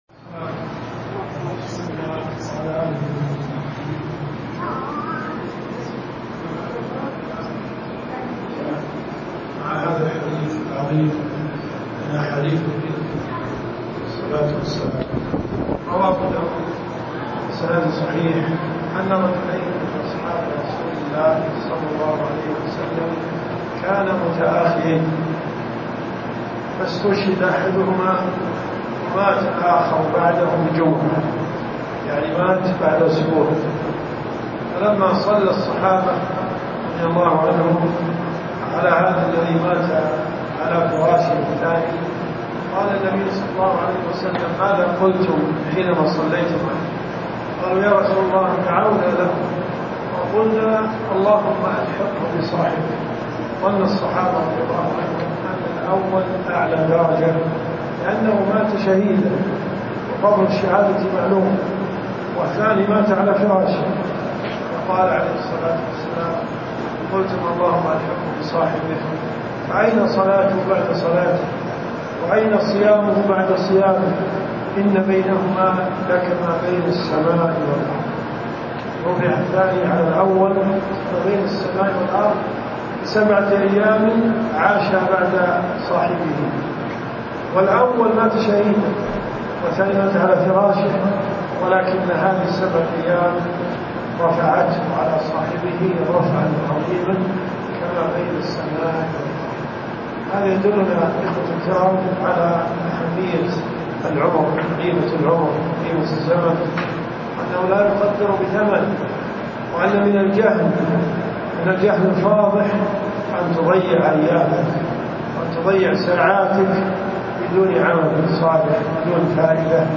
كلمات المساجد . مغرب السبت .